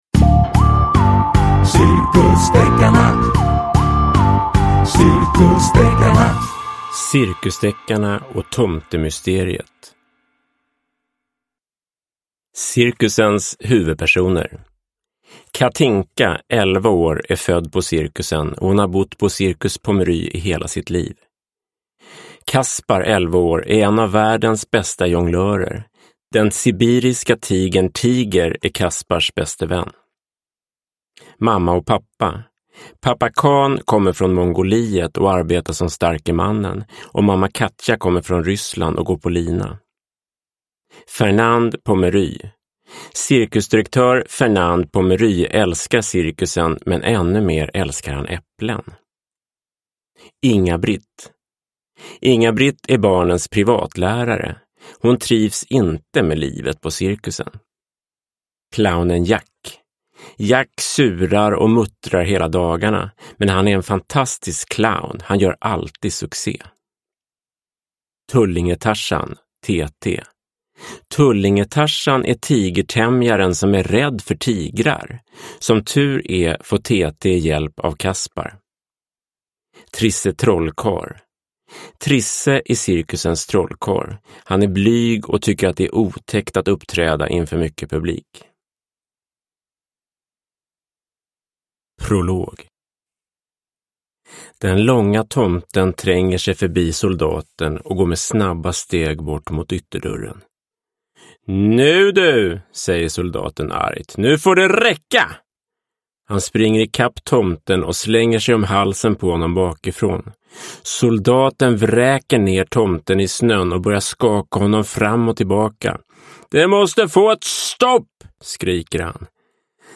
Cirkusdeckarna och tomtemysteriet – Ljudbok – Laddas ner